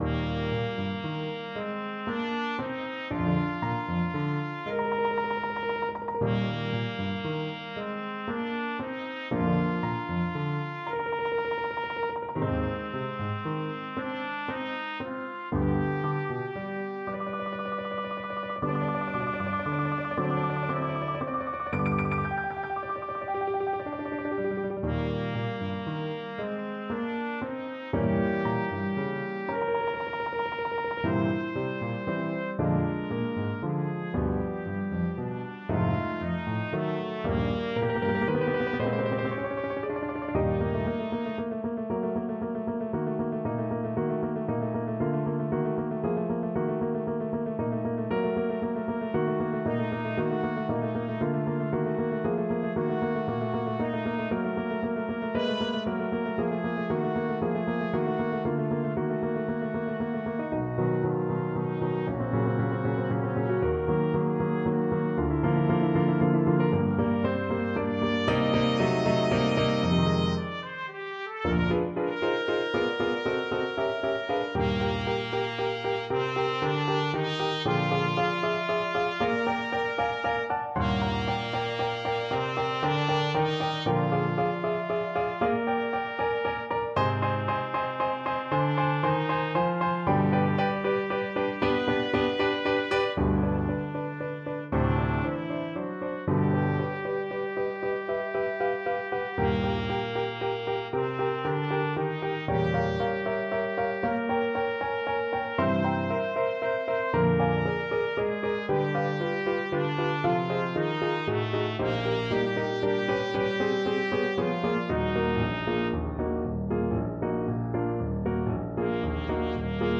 Andantino = 116 (View more music marked Andantino)
6/8 (View more 6/8 Music)
Classical (View more Classical Trumpet Music)